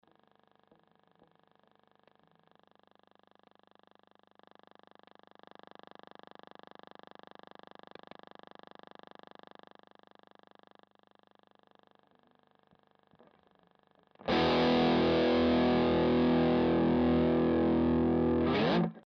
Hallo, seit ein paar Monaten habe ich ein nerviges Störgeräusch im Mini Rectifier. Hat das schon mal jemand gehabt und konnte es beseitigen?